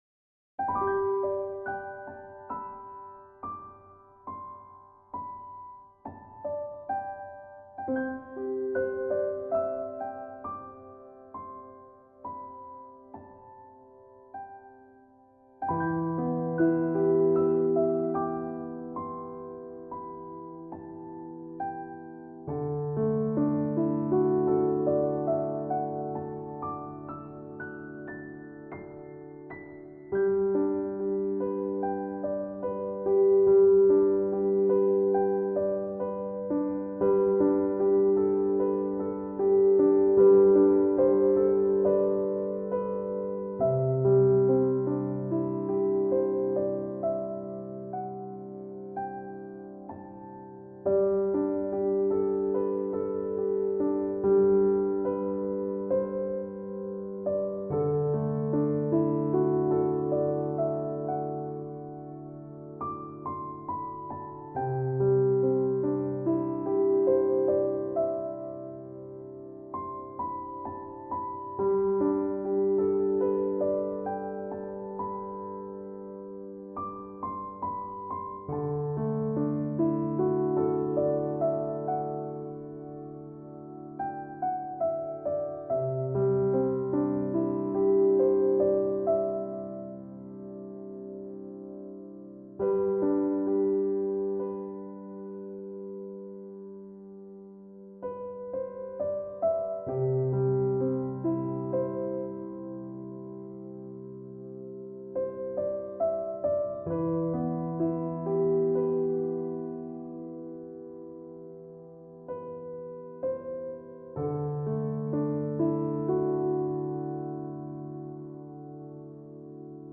Une heure de piano relaxant pour yoga, spa, massage et détente